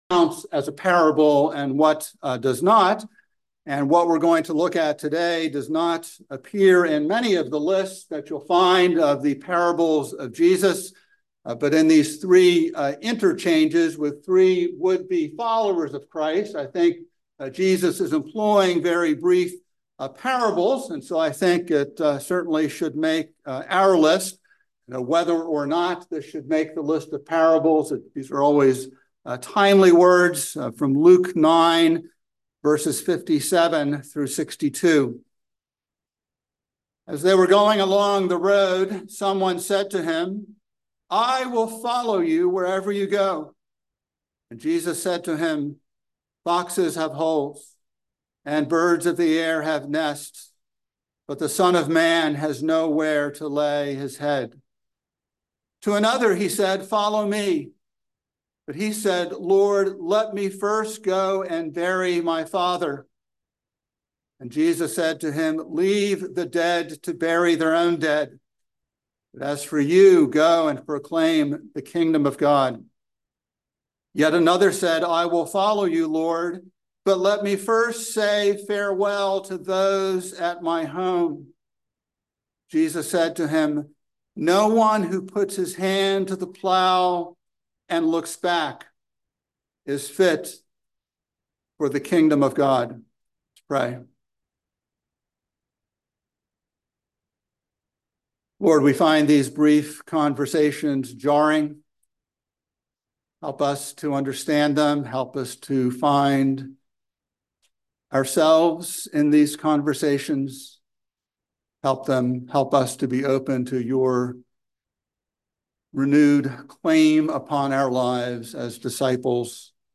by Trinity Presbyterian Church | Mar 14, 2024 | Sermon